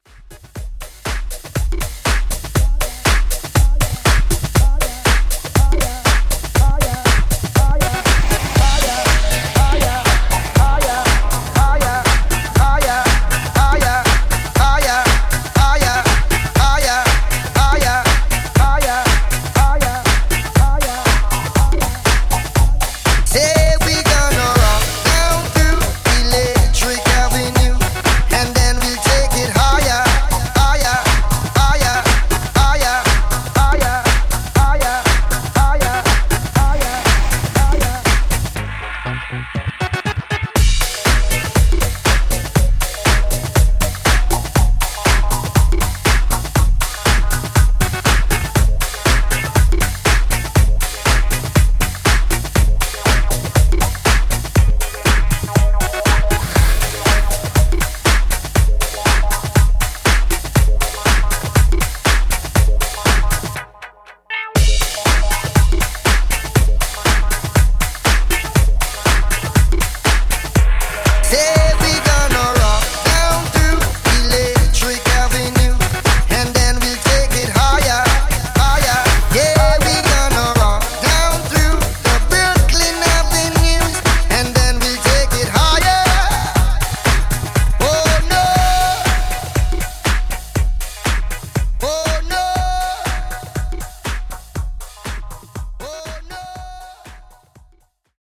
Classic House Mix)Date Added